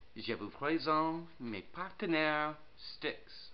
And so the Internet was getting more fun as well because we could put some audio files and so Tommy introduced every section of the web site with a greeting phrase in French!
Les fichiers sonores plus haut ont été enregistrés par Tommy dans son studio pour présenter chaque section du site.